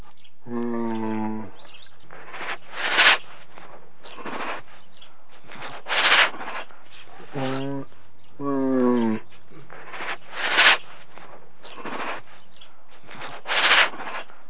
دانلود صدای حیوانات جنگلی 92 از ساعد نیوز با لینک مستقیم و کیفیت بالا
جلوه های صوتی